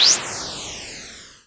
FlyEngine3.wav